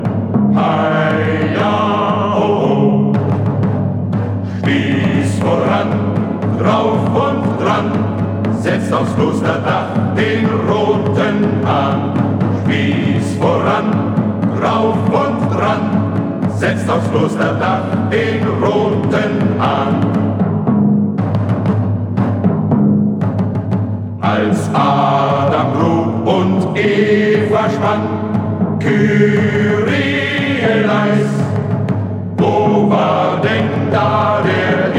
Жанр: Фолк-рок
# Traditional Folk